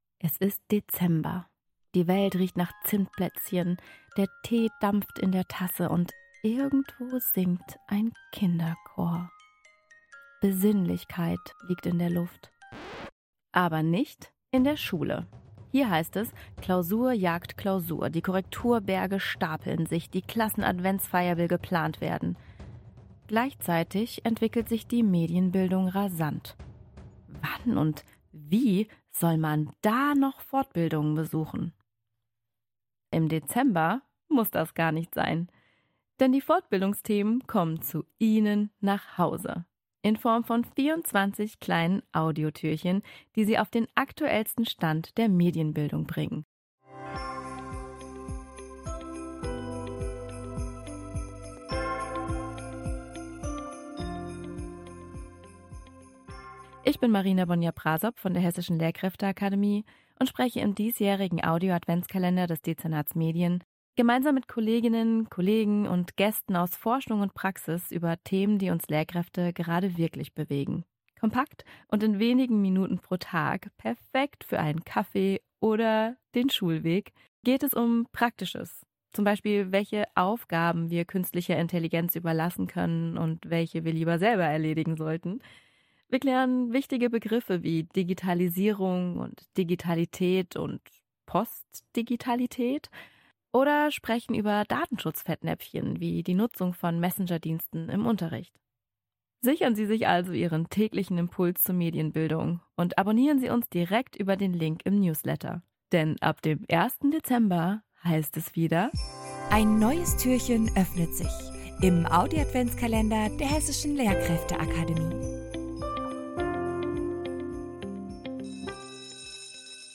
Teaser zum Adventskalender hinein und lassen Sie sich auf die vielfältigen Inhalte einstimmen.
Fortbildende aus dem Dezernat Medien berichten aus ihrer täglichen Arbeit mit und in Schulen, teilen ihre Erfahrungen, Best Practices und konkrete Tipps.